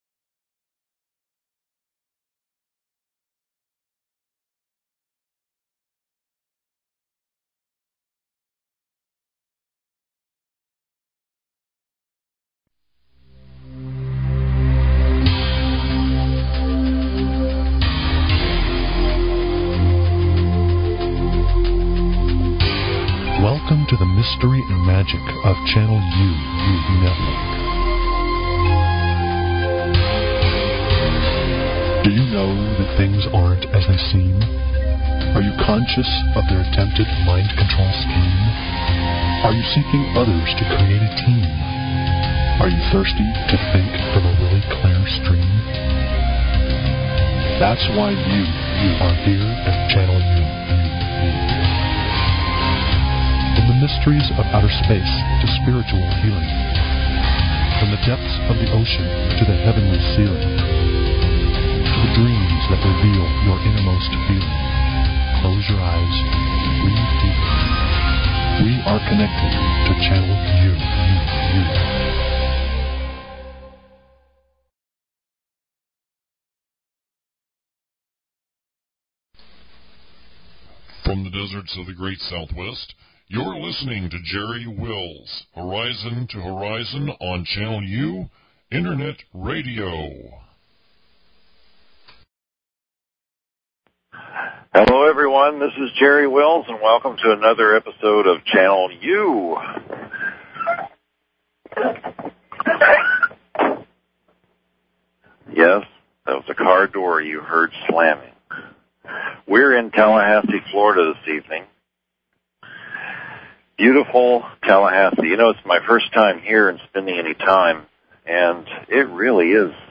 Talk Show Episode, Audio Podcast, Channel_U and Courtesy of BBS Radio on , show guests , about , categorized as